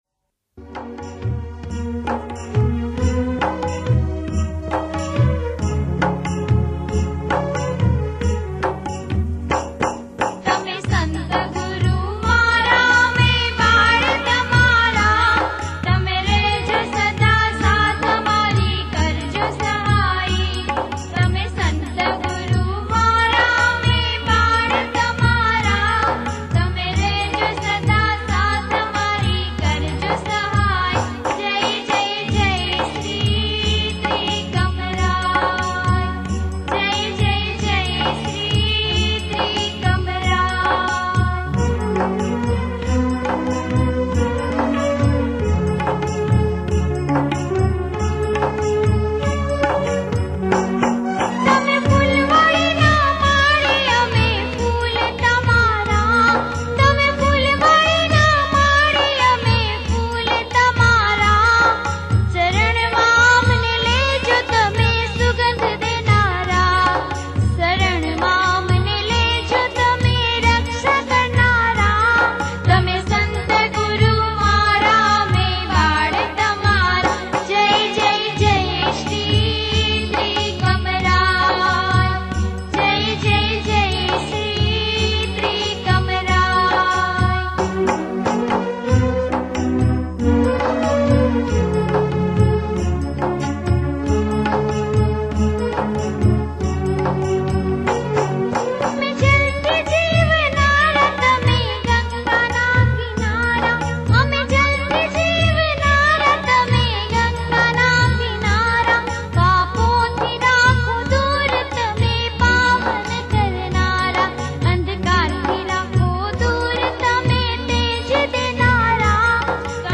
Sant Trikamjibapu Bhajans